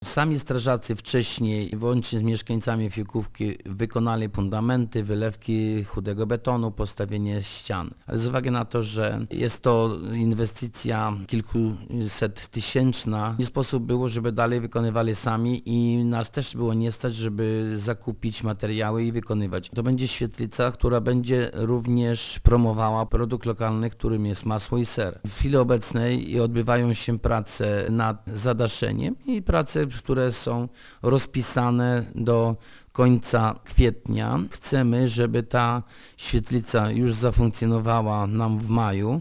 Wójt Jerzy Kędra przypomina, że inwestycję rozpoczęli sami mieszkańcy: